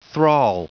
Prononciation du mot thrall en anglais (fichier audio)
Prononciation du mot : thrall